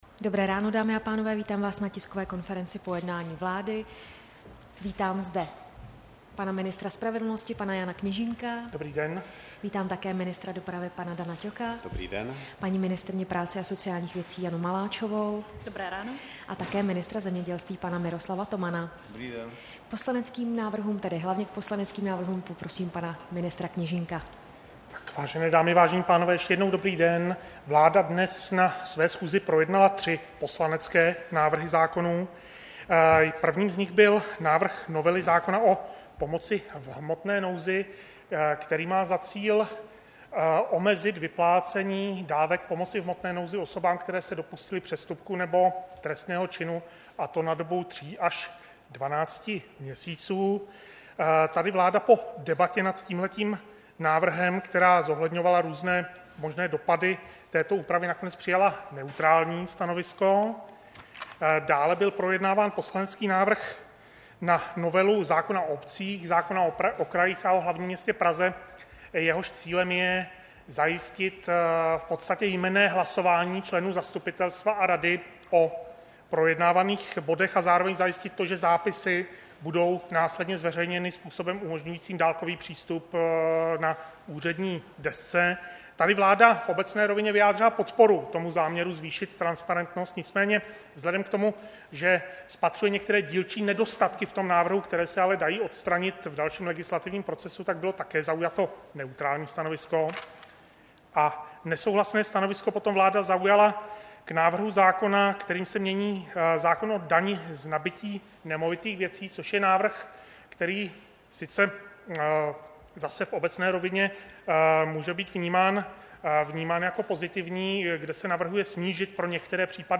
Tisková konference po jednání vlády, 24. října 2018